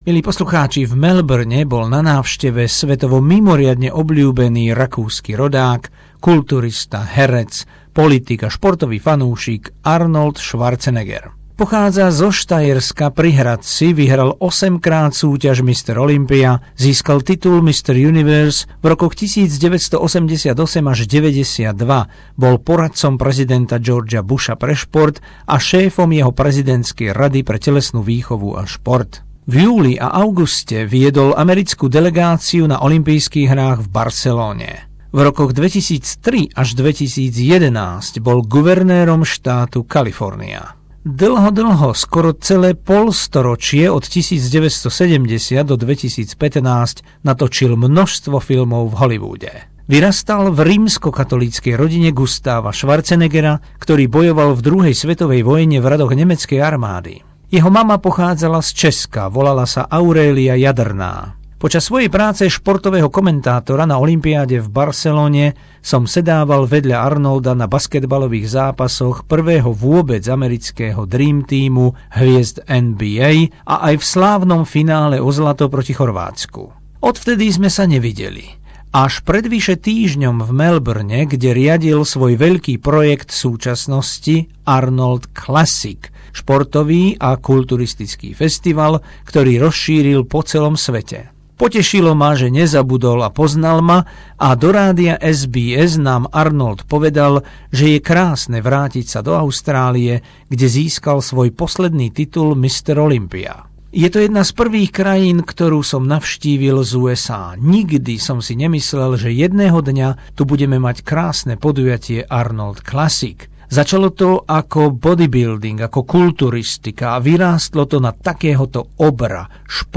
Rakúsky rodák, americký herec, kulturistický majster sveta, politik a podnikateľ Arnold Schwarzenegger v slovenskom vysielaní Rádia SBS